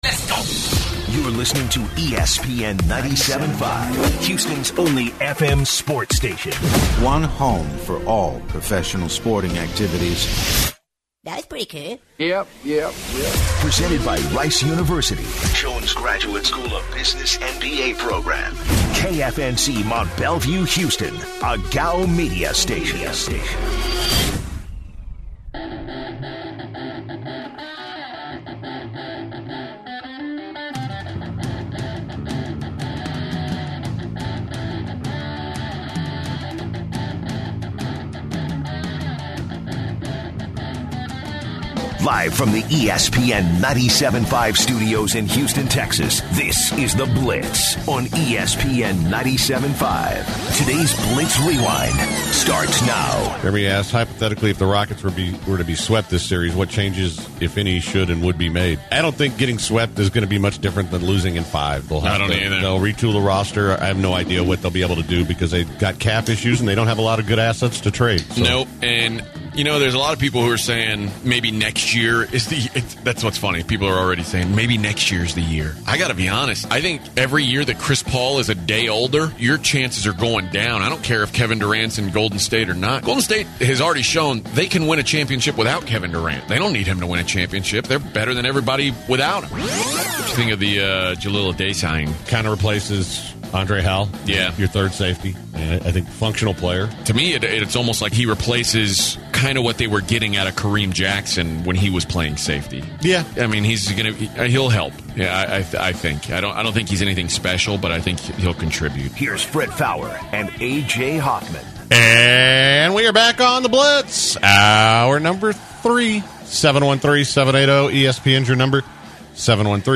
Facebook Twitter Headliner Embed Embed Code See more options The guys start the third off talking about prize money for betting and Jeopardy. They answer calls about Rockets, Game of Thrones, and Avengers Endgame. The guys start talking about minor league baseball players and their stats.